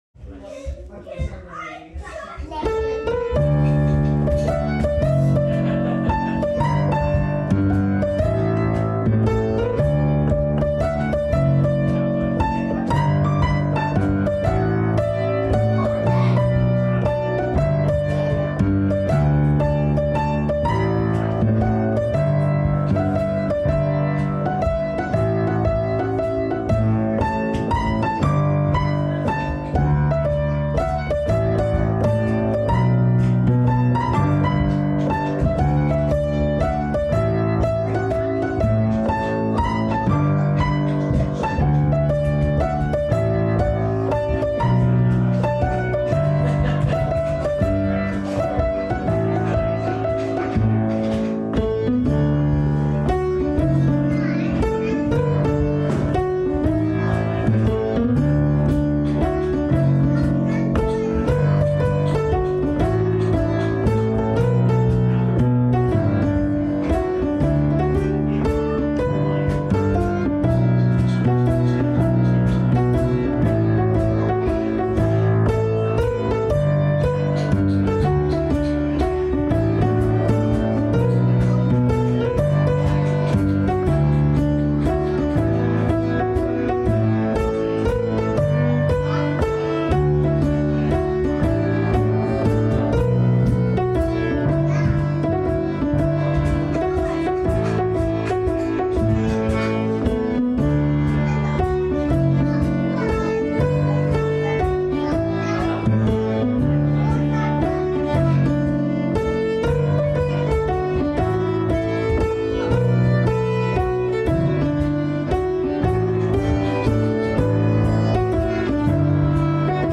noras-waltz.mp3